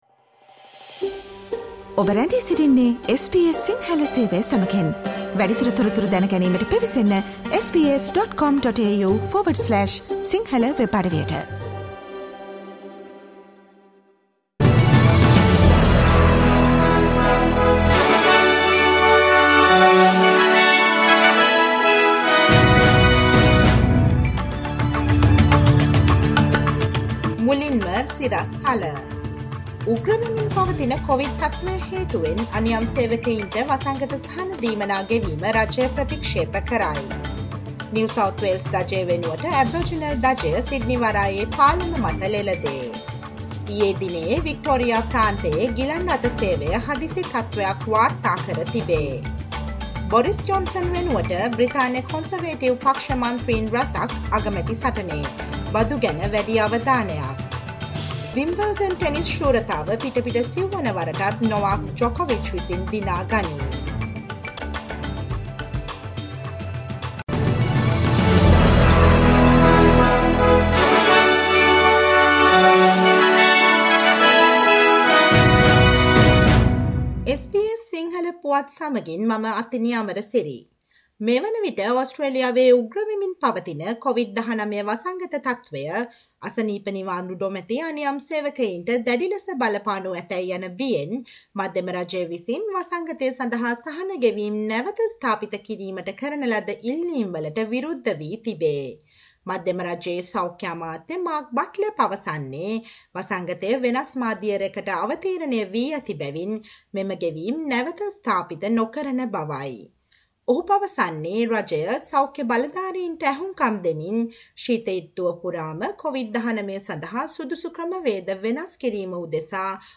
දෙස් විදෙස් පුවත් සහ ක්‍රීඩා පුවත් රැගත් SBS සිංහල සේවයේ 2022 ජූලි 11 වන සඳුදා වැඩසටහනේ ප්‍රවෘත්ති ප්‍රකාශයට සවන් දීමට ඉහත ඡායාරූපය මත ඇති speaker සලකුණ මත click කරන්න.